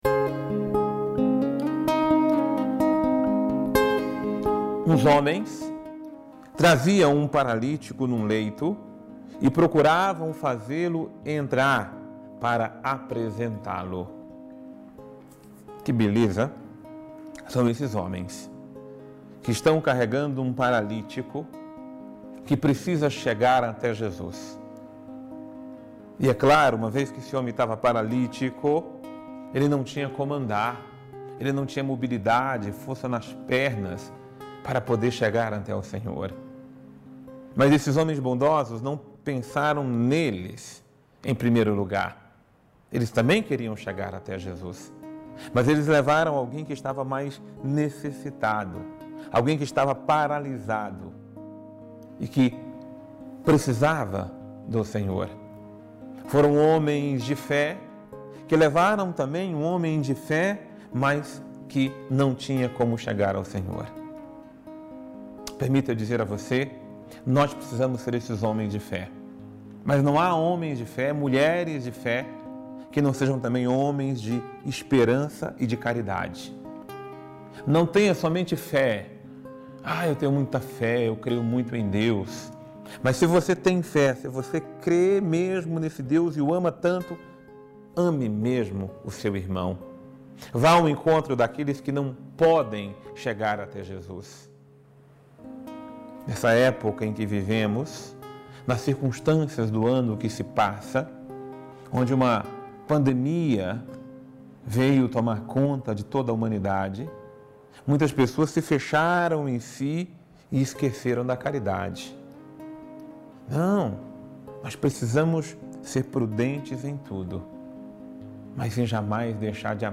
Homilia diária | Levemos as pessoas ao encontro de Jesus